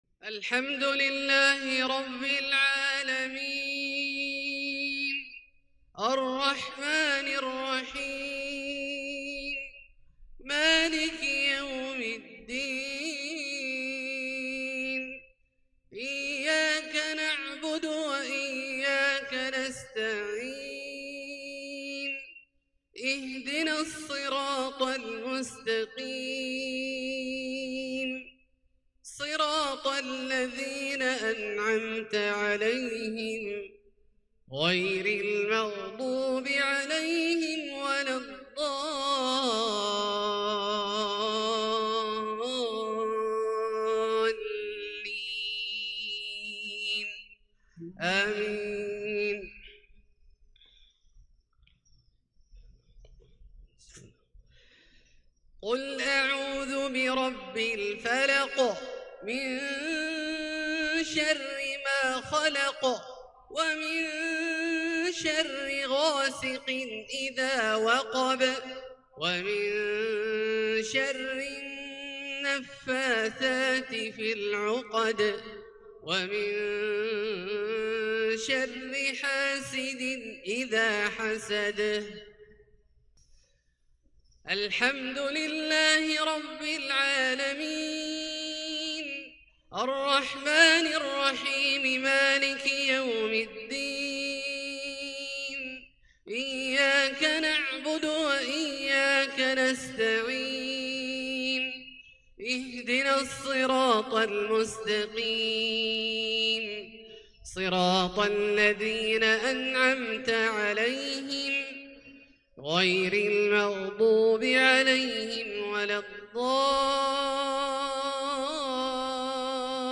6-3-2021 maghreb prayer from surat al-nas & al-falaq > H 1442 > Prayers - Abdullah Al-Juhani Recitations